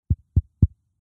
Звуки лагающего микрофона